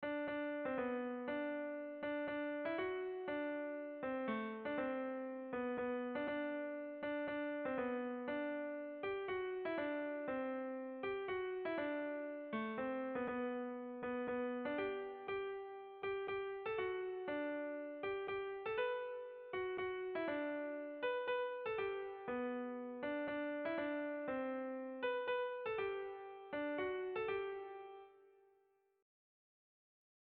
Sentimenduzkoa
Zortziko handia (hg) / Lau puntuko handia (ip)
A-B-C-D